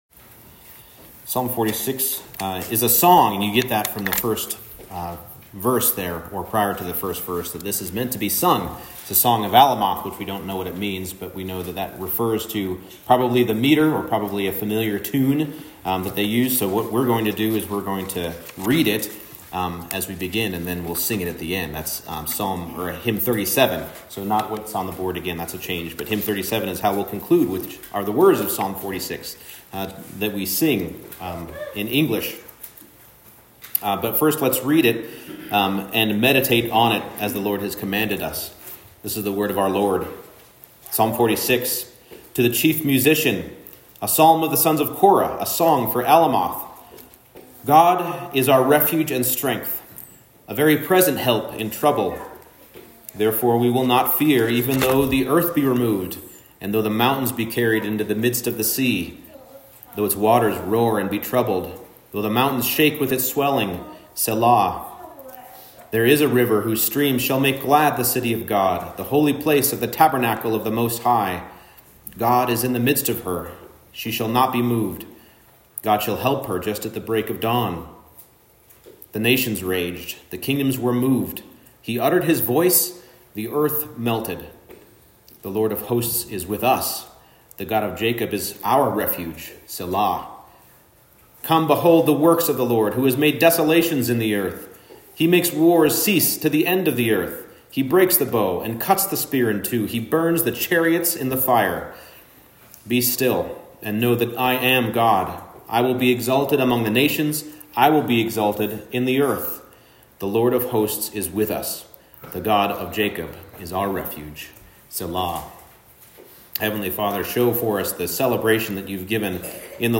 Psalm 46 Service Type: Morning Service The Lord of Hosts brings order from chaos.